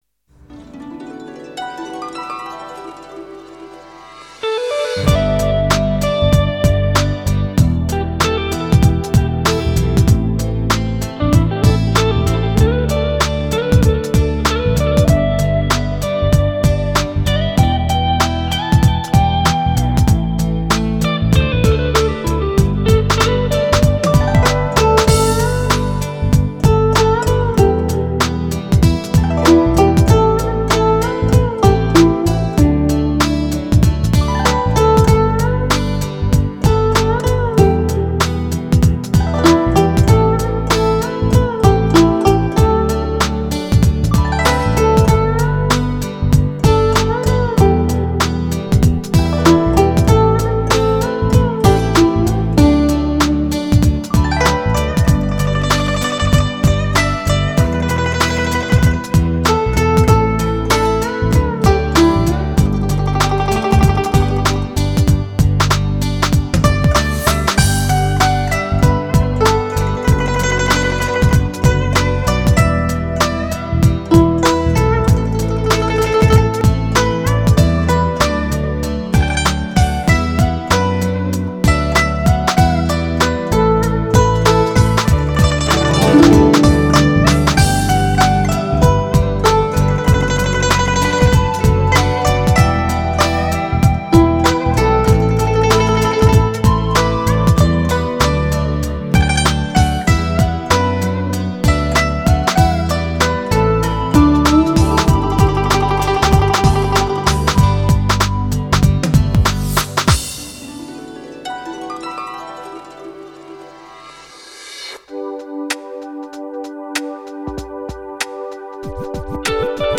浪漫的歌曲、浪漫的古筝、浪漫的演绎......